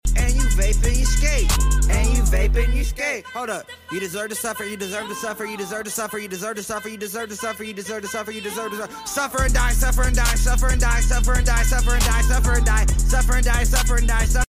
and the quality sucks